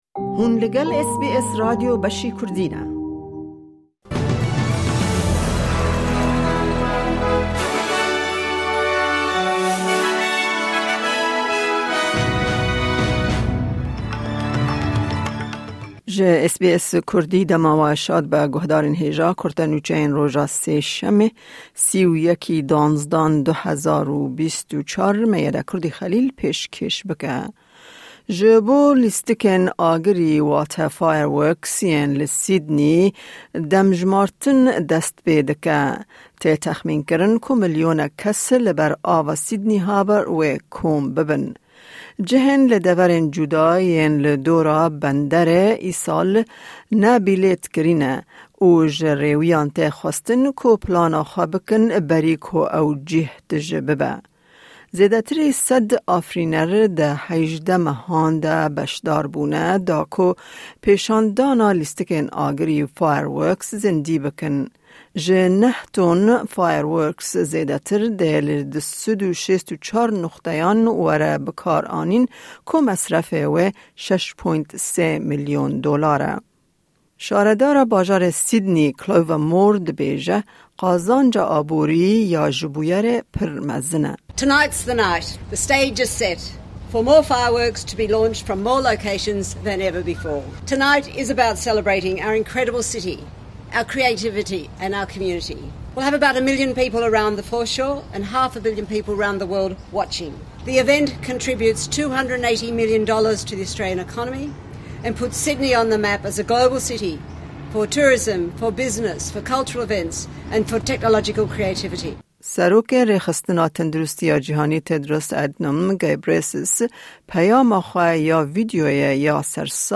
Kurte Nûçeyên roja Sêşemê 31î Kanûna 2024